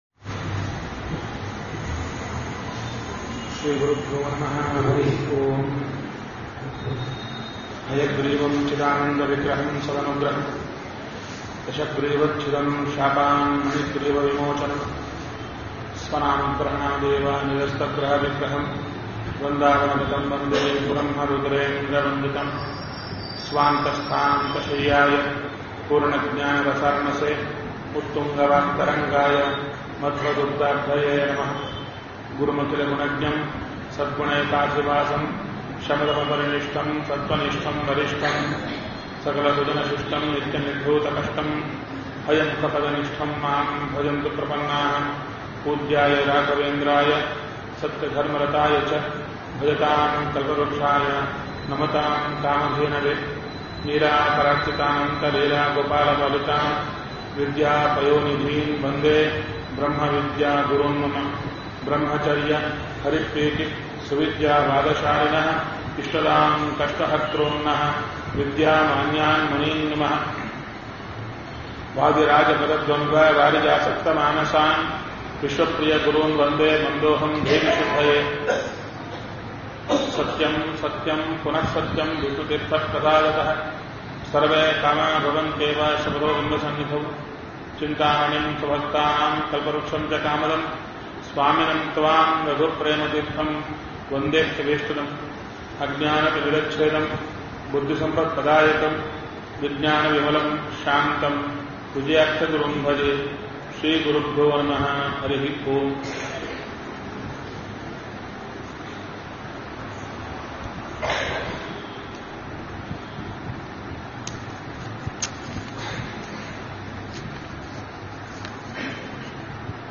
Spiritual discourses, audio messages, events, and downloadable resources from Kurnool Achars Chintana.
Pravachana